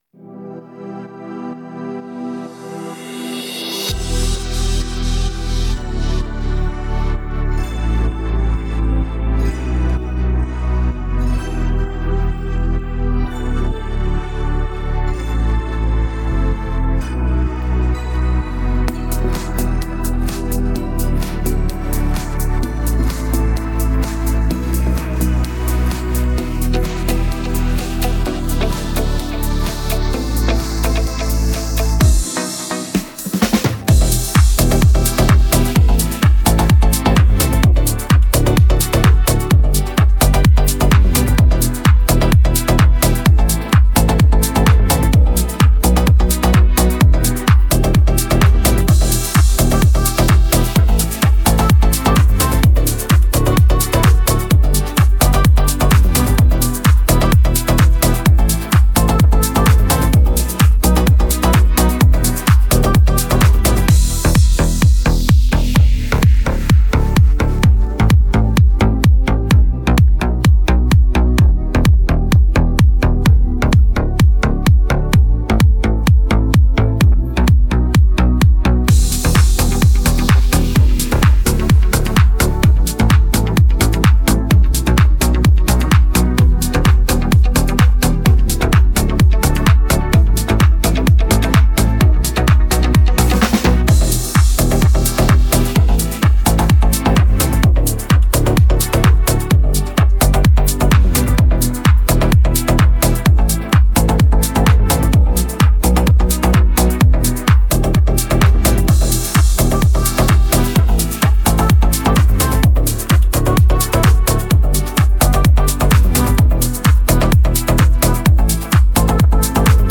Слушать или скачать минус